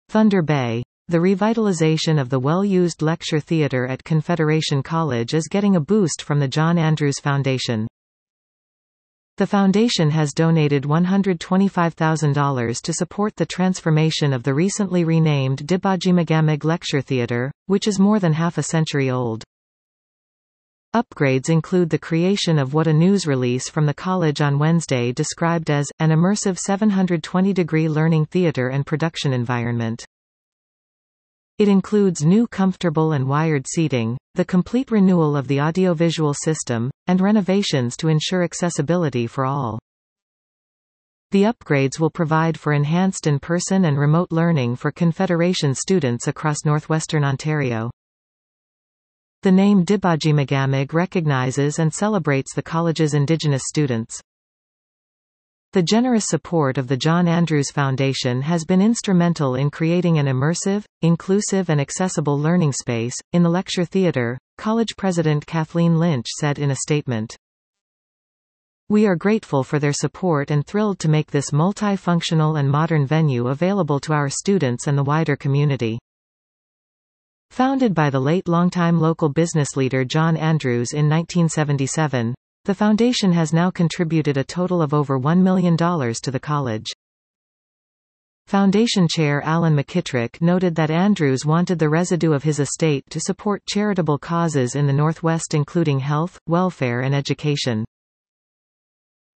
(Submitted photo) Listen to this article 00:01:39 THUNDER BAY —The revitalization of the well-used lecture theatre at Confederation College is getting a boost from the John Andrews Foundation.